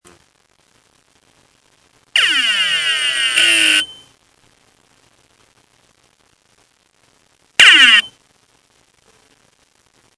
Vous trouverez ci-dessous quelques échantillons sonores qui illustrent ce que vous pouvez entendre lorsque votre disque dur présente des signes de faiblesses (ou plus, et donc certainement trop tard).
Différents disques produisent différents sons.
head_stuck_to_platter-Phaser_Noise.mp3